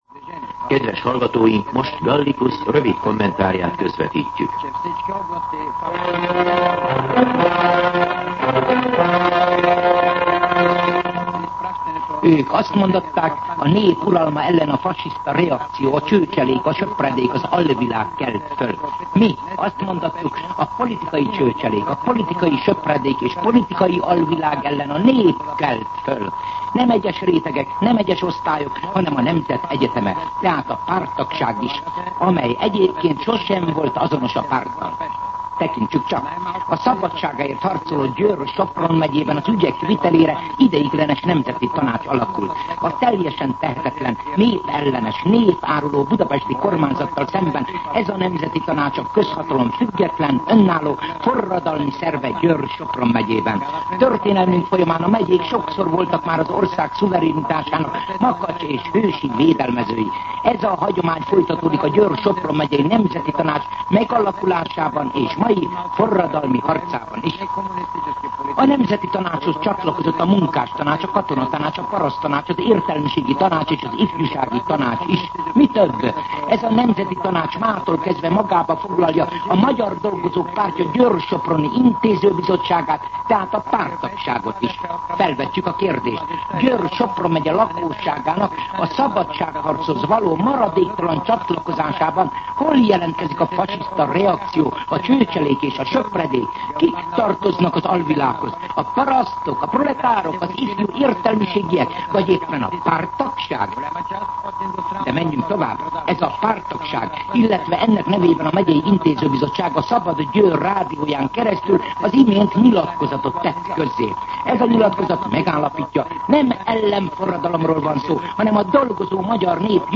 MűsorkategóriaKommentár